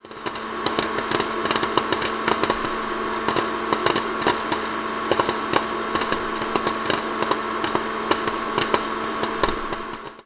popcorn machine